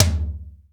RS TOM 1-1.wav